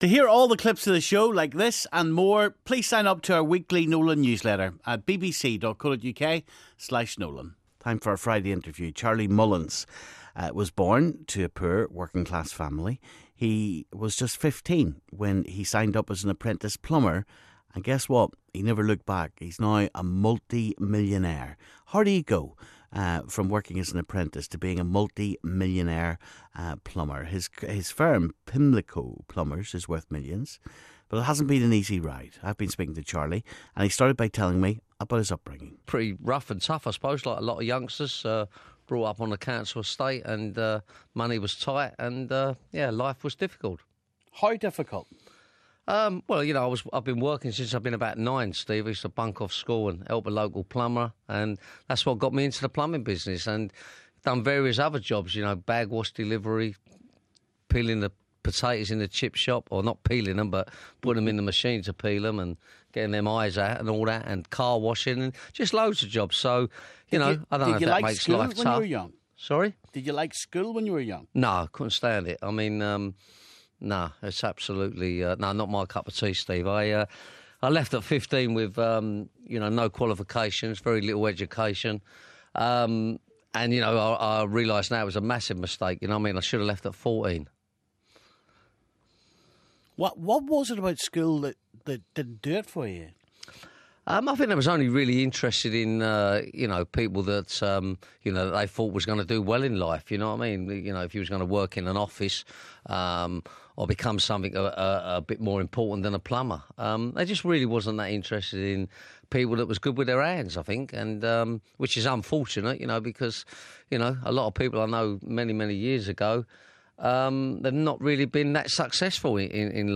Big Friday Interview: Charlie Mullins, from rags to riches